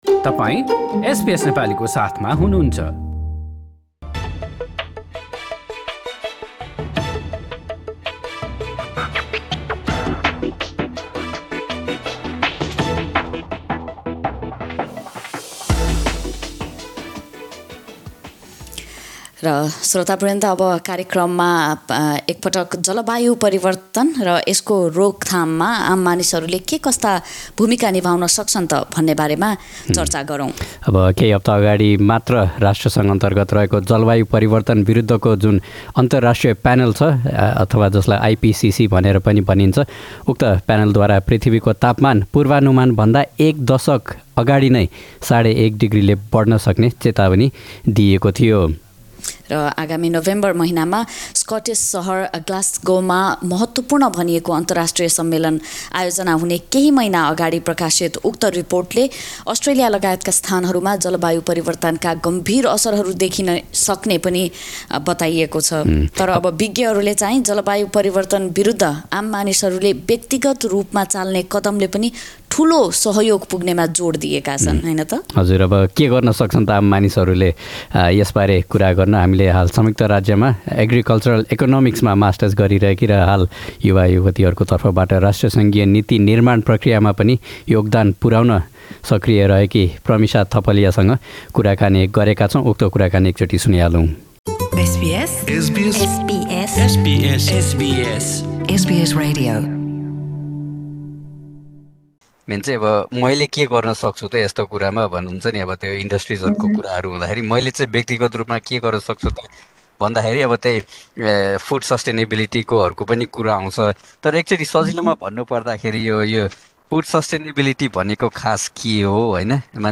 Clik on the media player on top of this page to listen to our conversation in Nepali language.